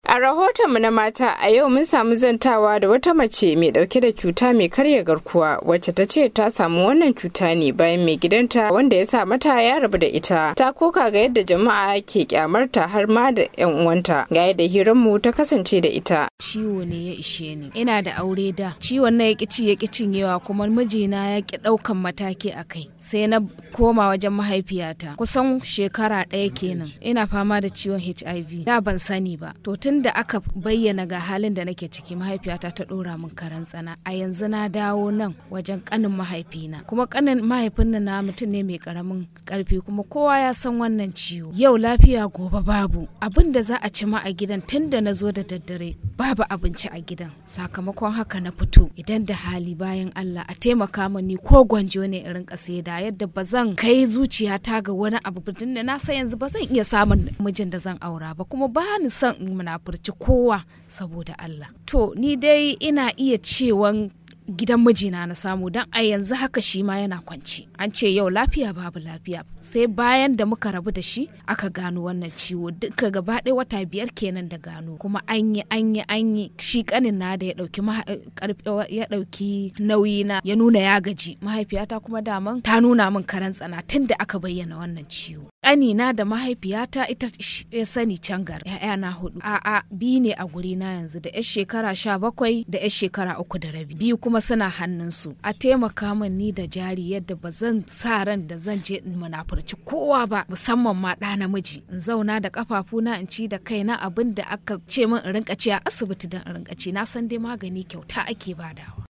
Dandalin VOA ya zanta da wata mata da mijinta ya samata cutar mai karyar garkuwar jiki kuma daga baya suka rabu.